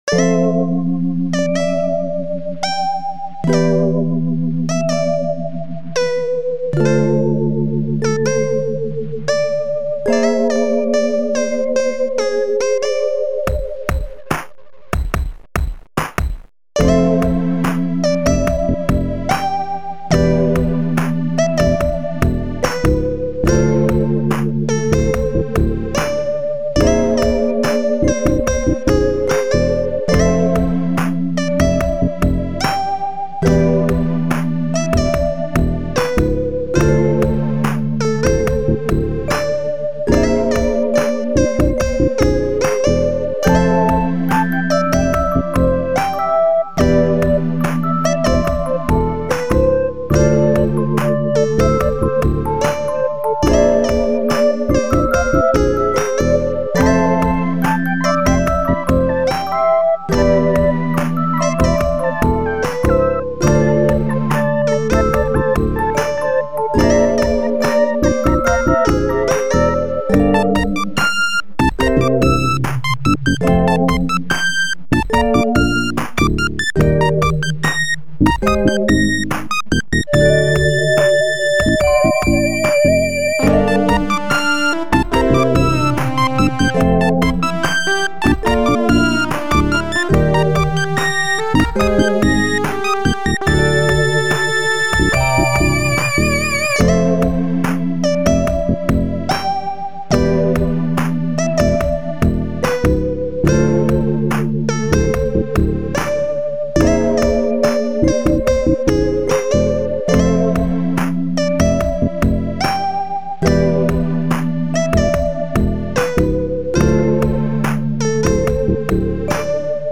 This is just a cute little loop, reminicist of tracks by Snail's House or Masashi Kageyama's soundtrack for Mr. Gimmick.